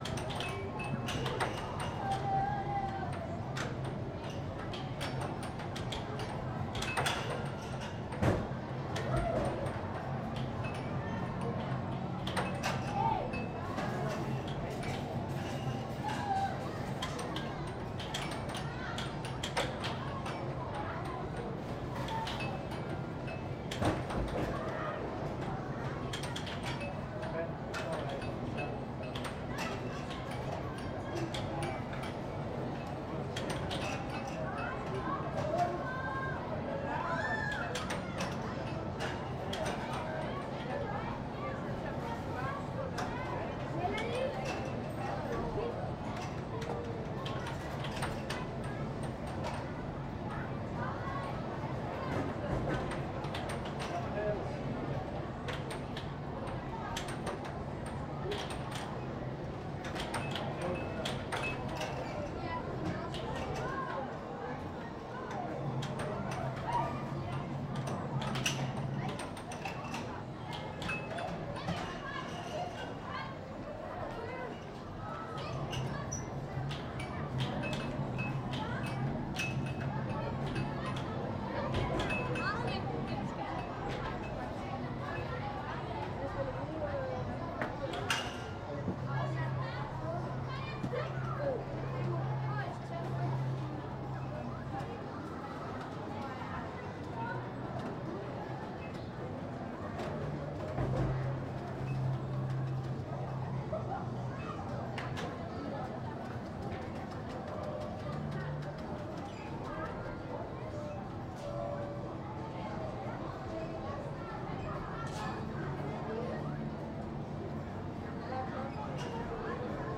air hockey kids indoor playground 2012-10-18
air air-hockey chatter Denmark ding Dk game games sound effect free sound royalty free Sound Effects